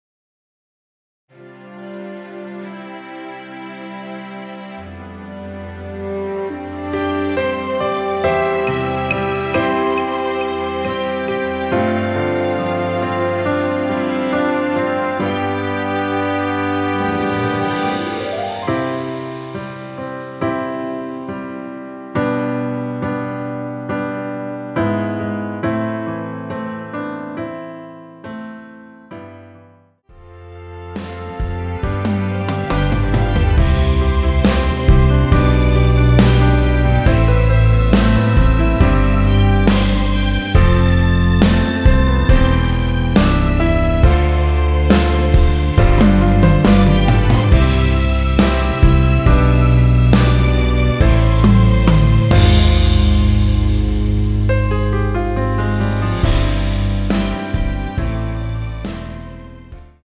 원키에서(-1)내린 MR입니다.
◈ 곡명 옆 (-1)은 반음 내림, (+1)은 반음 올림 입니다.
앞부분30초, 뒷부분30초씩 편집해서 올려 드리고 있습니다.
중간에 음이 끈어지고 다시 나오는 이유는